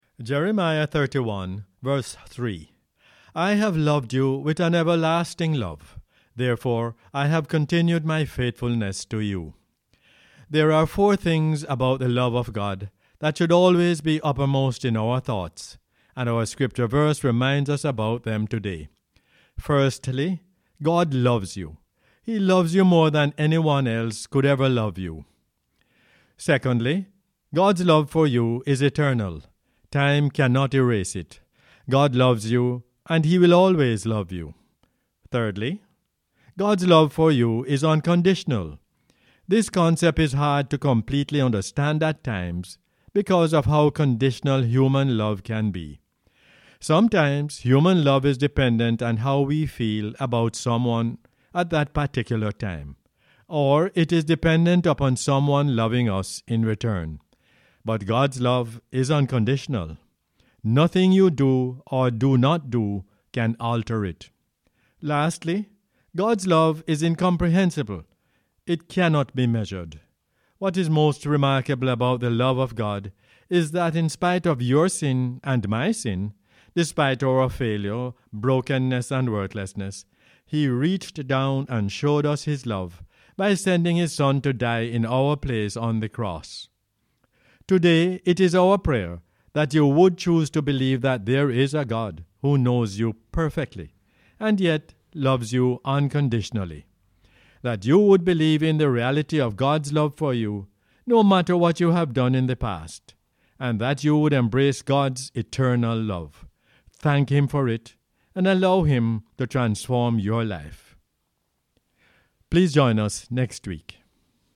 Jeremiah 31:3 is the "Word For Jamaica" as aired on the radio on 16 July 2021.